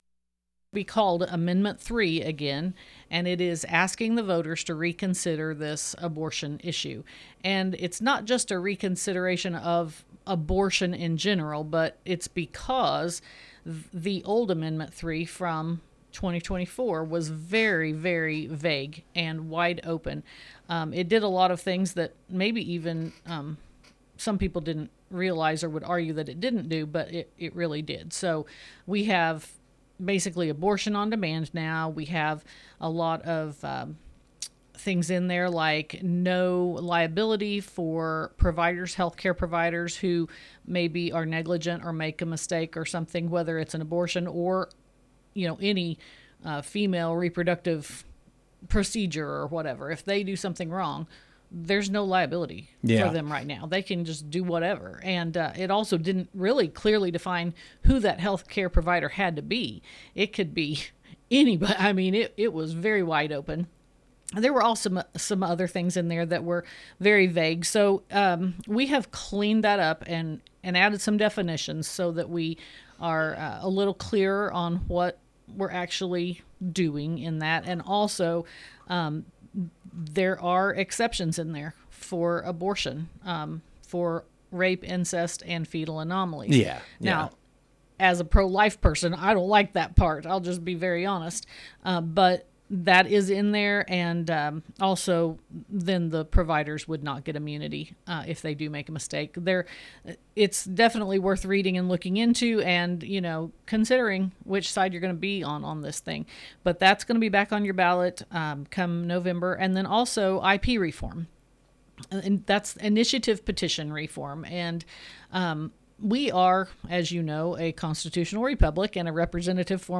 West Plains, MO. – Last Friday, Lisa Durnell, Missouri Representative of the 154th District, stepped into the studio to discuss her year in-office during 2025, and what she expects our of 2026.